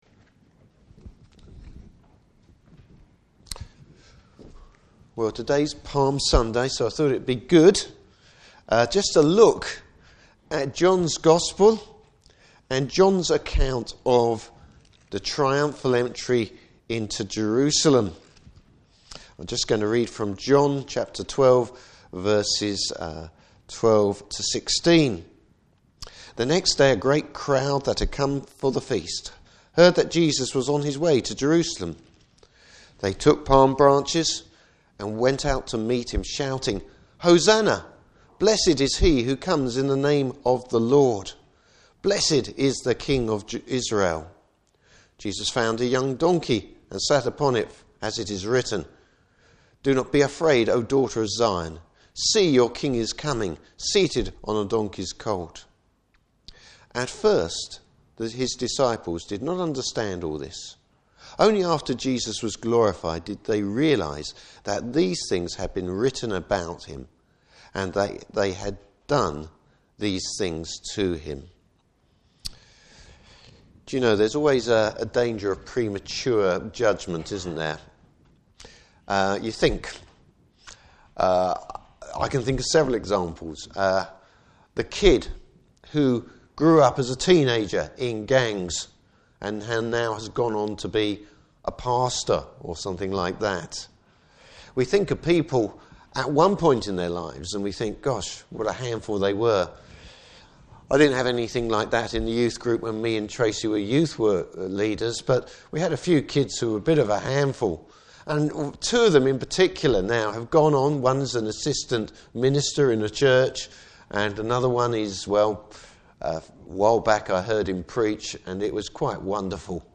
Service Type: Palm Sunday.